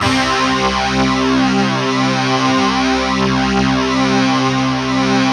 Index of /90_sSampleCDs/Optical Media International - Sonic Images Library/SI1_DistortGuitr/SI1_200 GTR`s